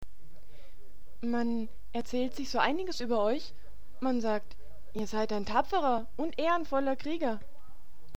Speaker's Market - Deutsche Sprecher (f)
Man sagt, Ihr seid ein tapferer Krieger Man sagt, Ihr seid ein tapferer Krieger(alte Frau) Wachen! ein Dieb!